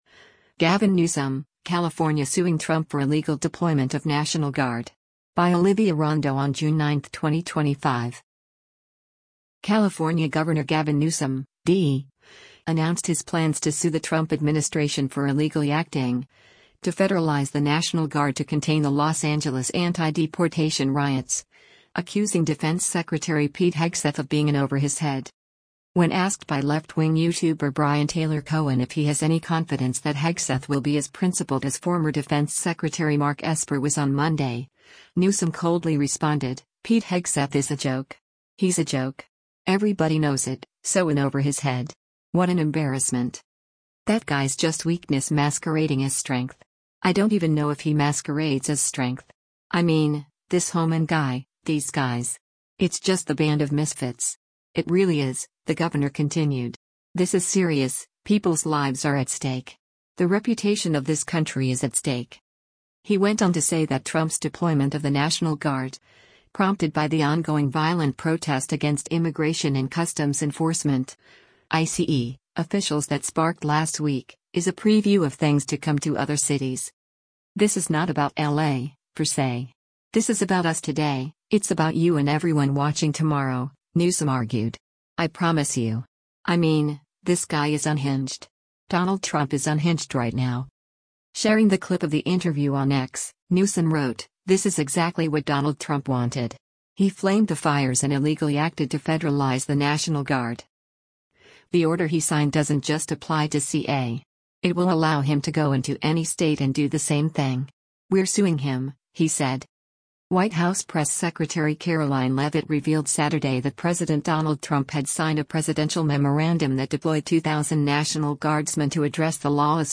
Sharing the clip of the interview on X, Newsom wrote, “This is exactly what Donald Trump wanted. He flamed the fires and illegally acted to federalize the National Guard.”